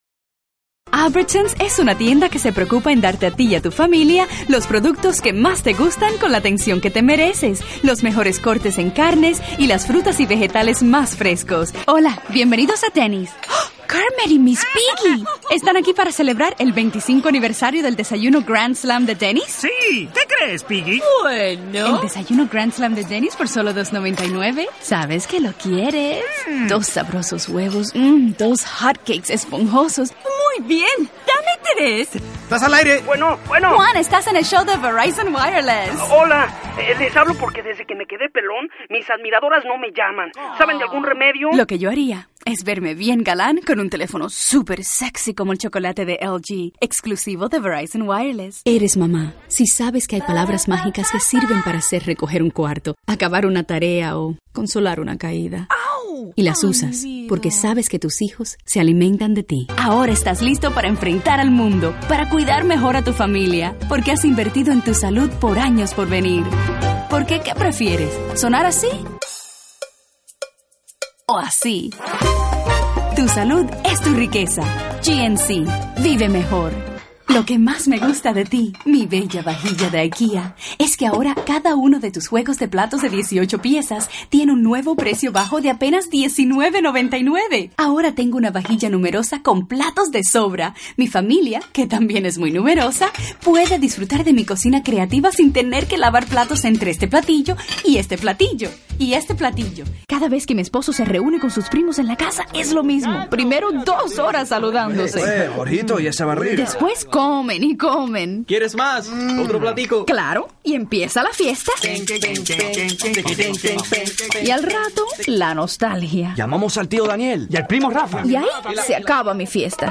chicago : voiceover : commercial : women